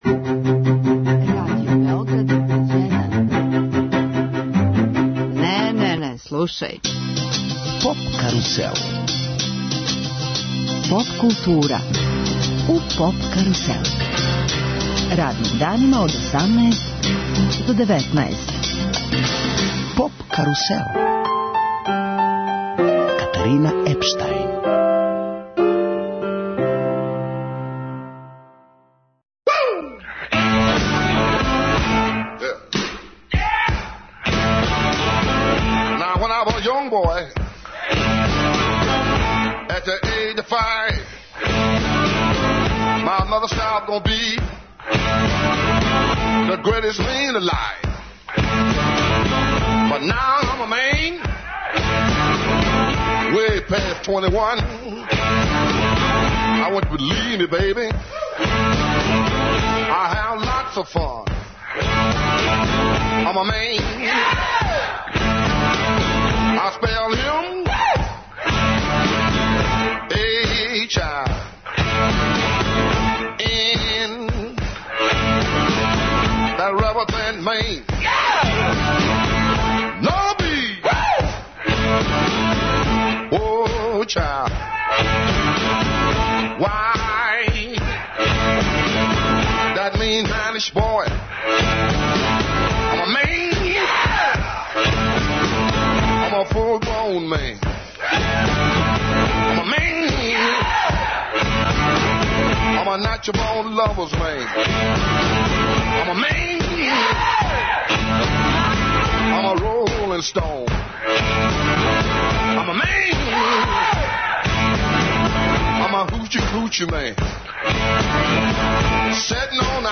Гост емисије је Жика Јелић, прослављени музичар и басиста ЈУ групе.